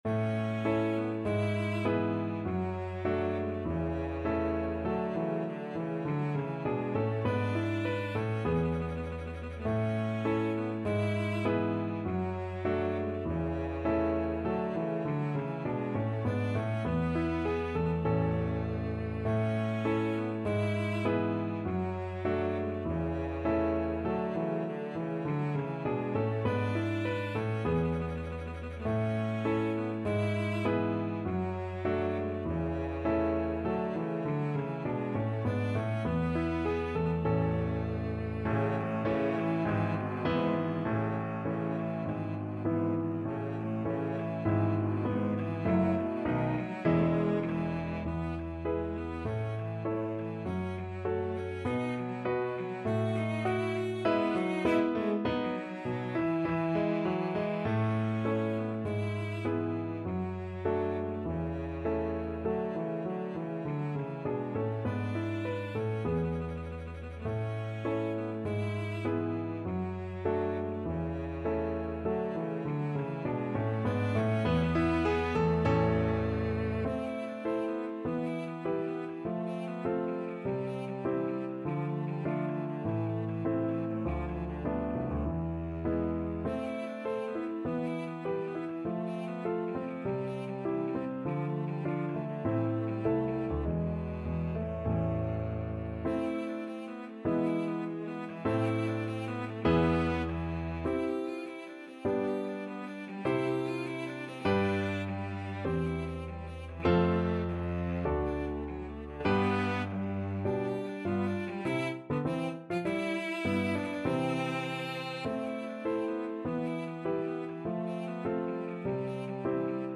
Cello
2/2 (View more 2/2 Music)
~ = 100 Allegretto
A major (Sounding Pitch) (View more A major Music for Cello )
Classical (View more Classical Cello Music)